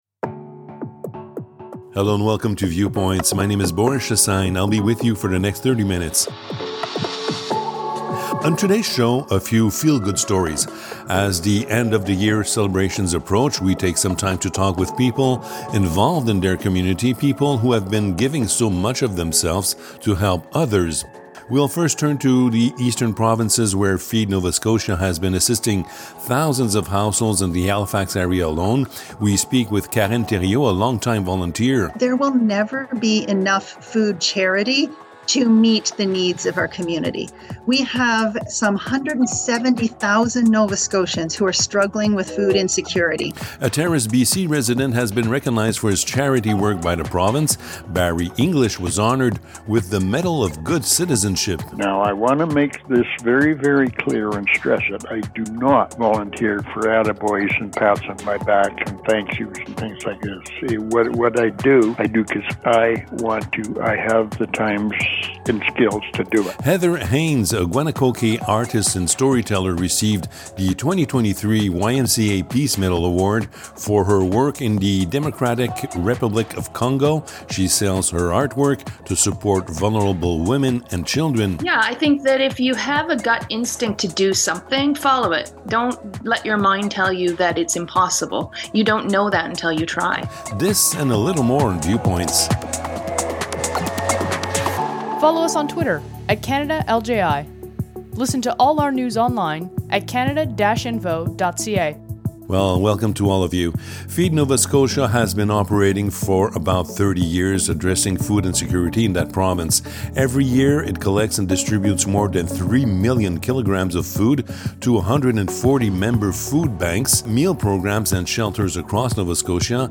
Episode #39 of the Viewpoints radio show
As the end of the year celebrations approach, we take some time to talk with community movers, people who have been giving so much of themselves to help others.